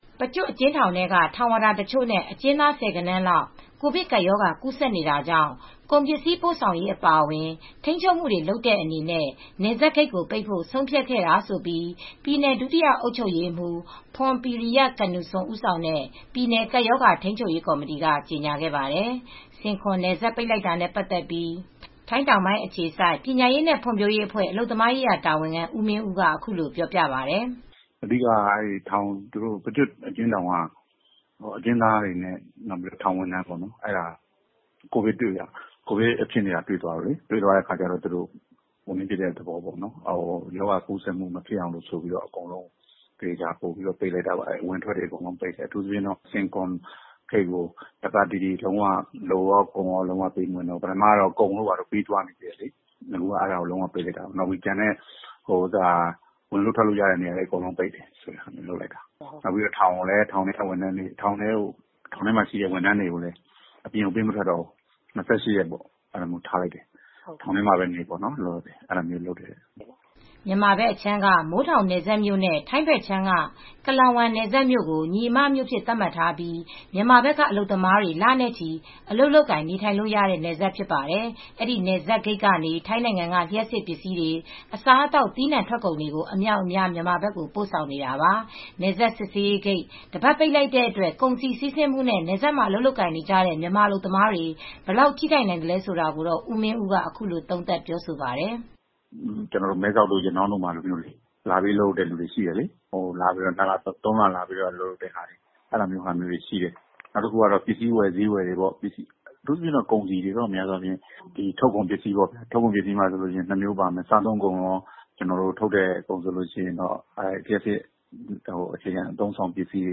ထိုငျးအခွစေိုကျသတငျးထောကျ